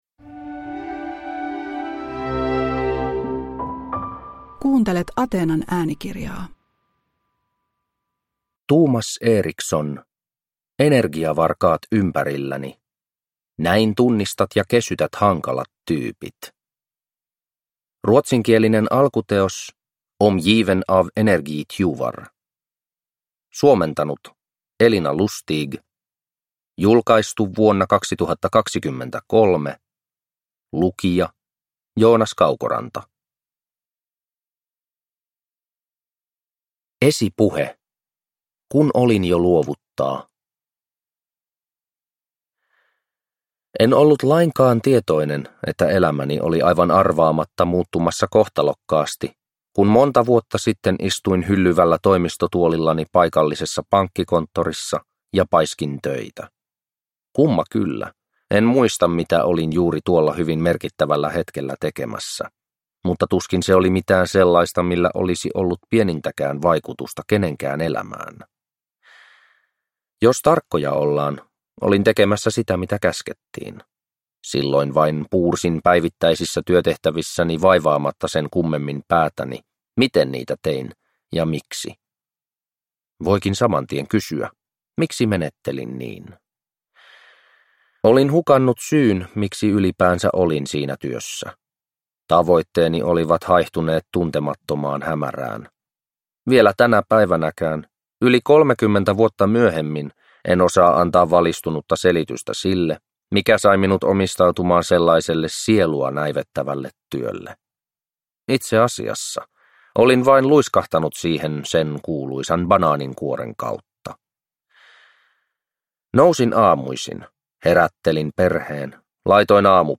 Energiavarkaat ympärilläni (ljudbok) av Thomas Erikson